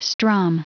Prononciation du mot strum en anglais (fichier audio)
Prononciation du mot : strum